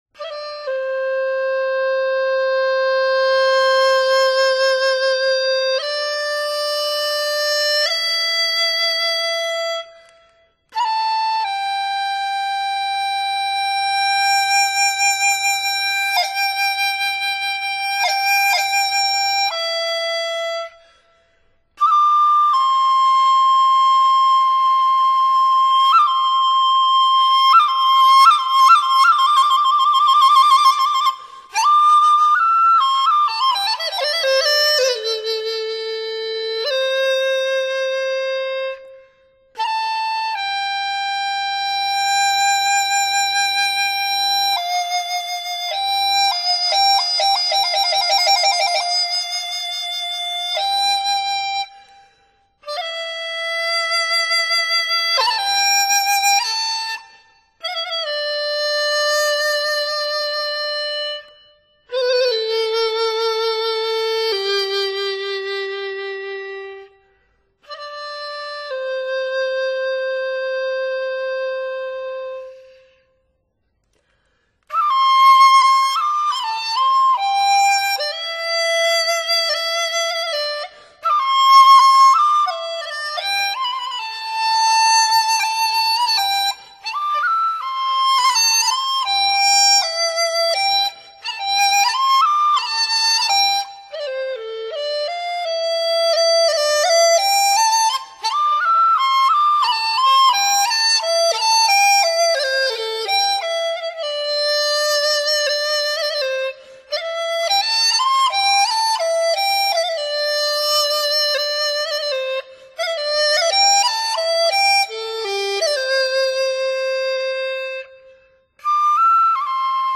笛，是民间流传最广的吹管乐器，常见的有梆笛及曲笛两种，梆笛音乐高亢、清脆，曲笛音色较淳厚、圆润。
笛声优美通泰，如云间飘来的仙乐，轻渺悠扬……
精选民间千年古曲，大师倾情独操演绎，细腻考究的录音品质，绝对划时代的原生态民乐巨作，发烧友一致感动推荐。
器乐独奏中间不加任何装饰与配器，纯粹表现民族乐器的原汁原味。